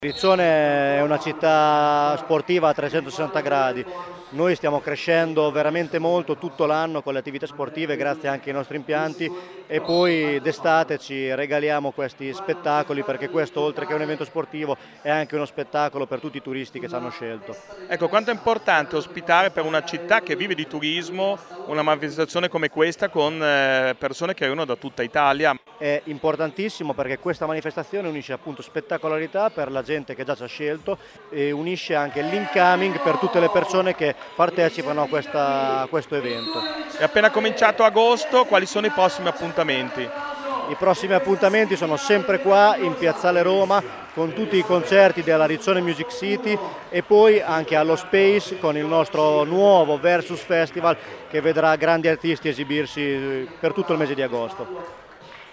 Ascolta l’intervista con SIMONE IMOLA assessore allo Sport del Comune di Riccione